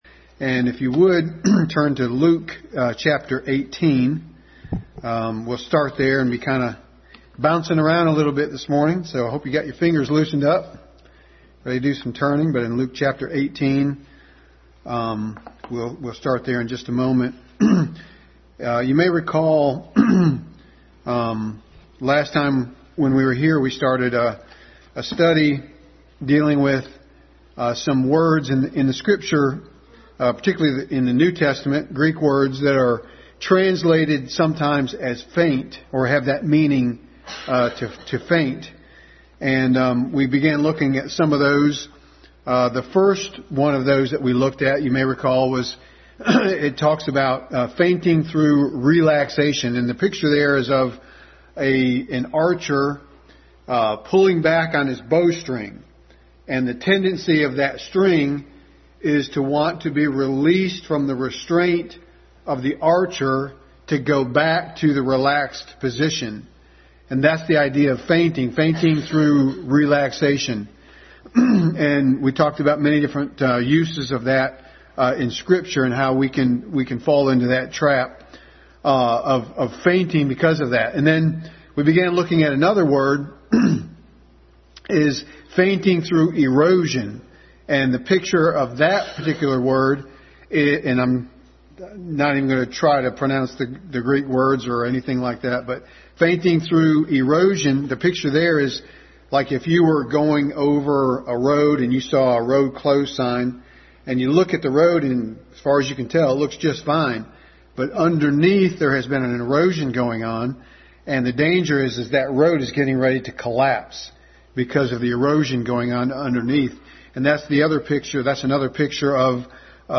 Passage: Luke 18:1-8 Service Type: Sunday School